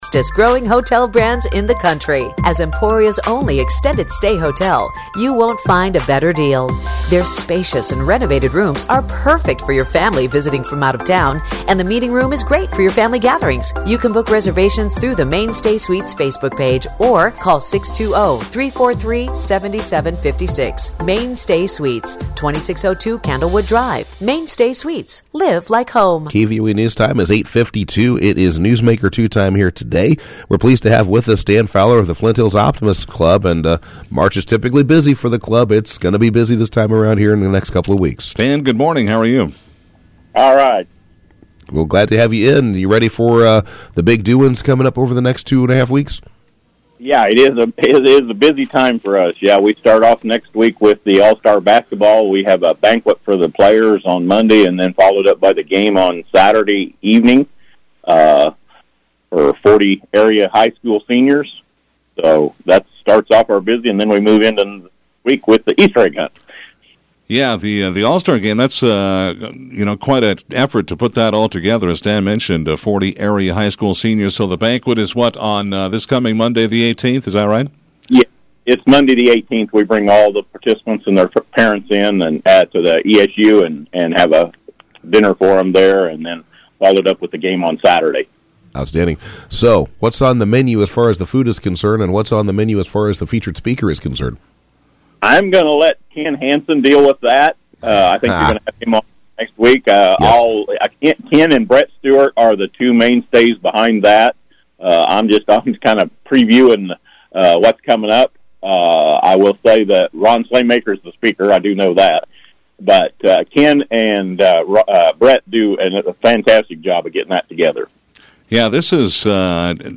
On KVOE’s Newsmaker 2 Segment Tuesday